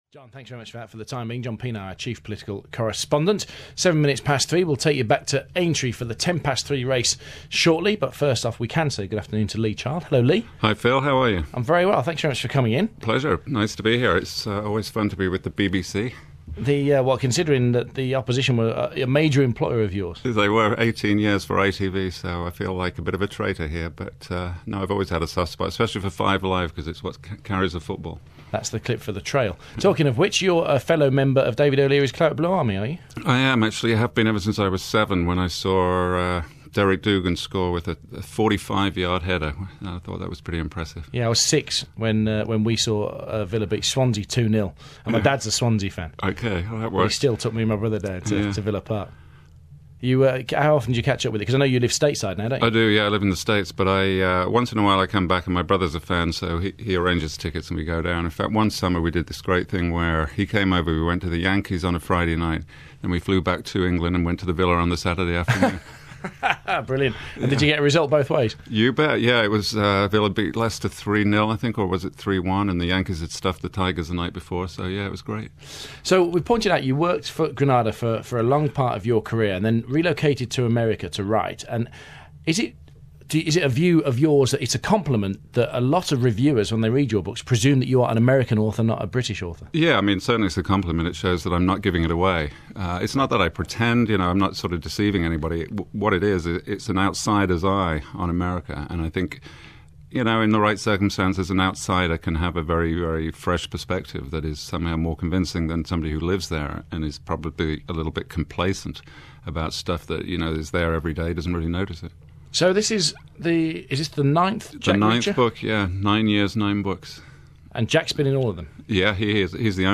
Then to the famous BBC building at White City for the Simon Mayo Book Club on Radio Five Live *. The format is that three pro reviewers and as many phone-ins from the public as they can get talk about the book they’ve been asked to read in the last week … which this week was One Shot (had to be, right, otherwise why would I have been there?).